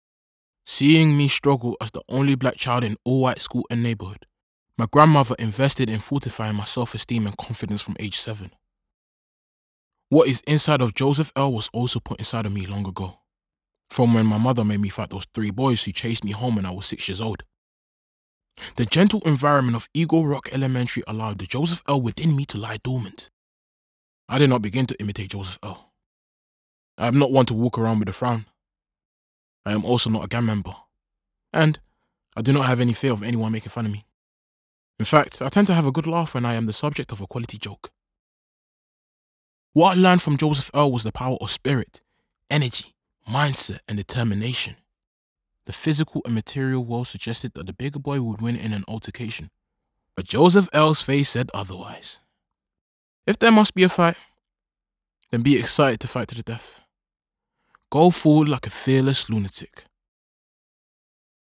Voice Reel
Narration Reel